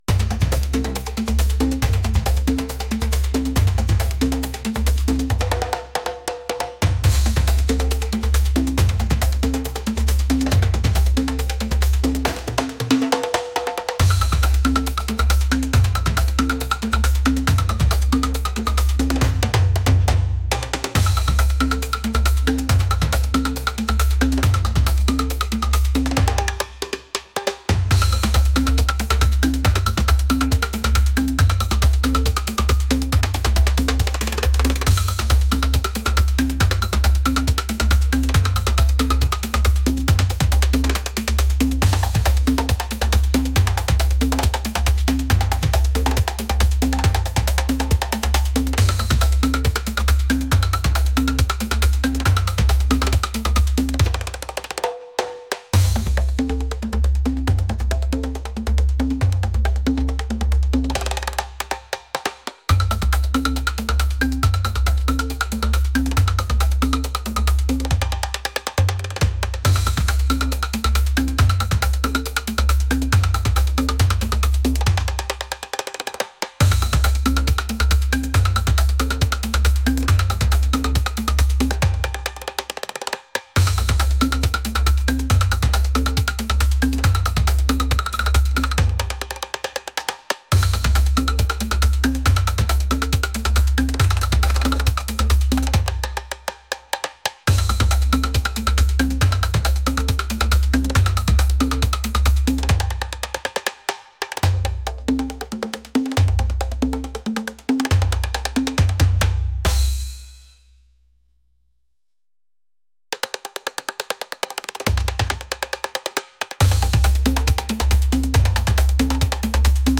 rhythmic | energetic